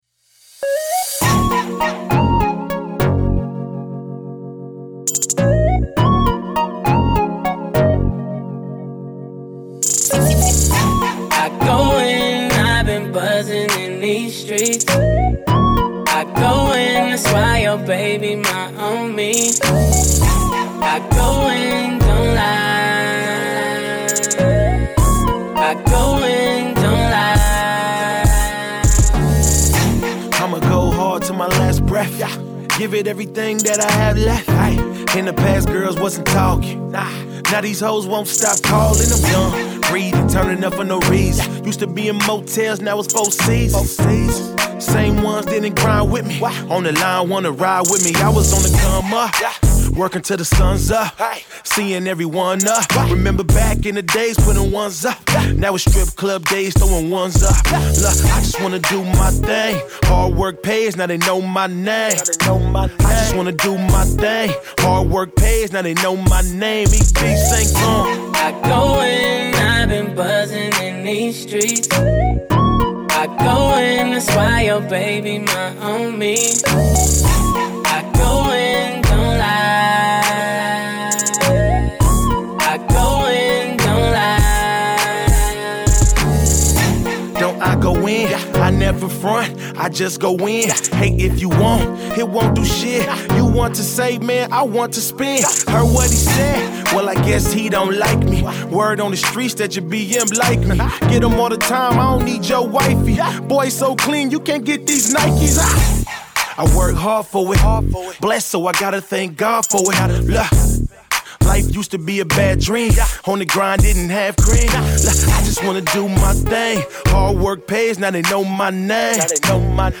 Compton rapper
R&B